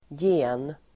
Uttal: [je:n]